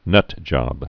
(nŭtjŏb)